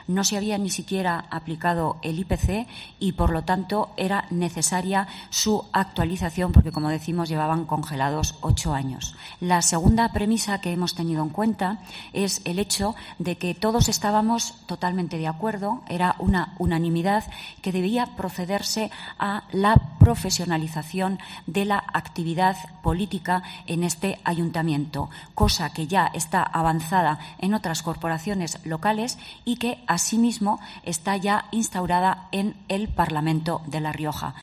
La portavoz del PP, Celia Sanz, ha hablado de la profesionalización de los 27 concejales de Logroño.